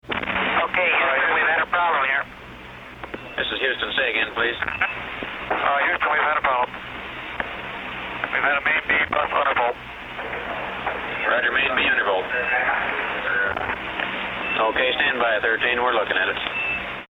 Space Sound Effects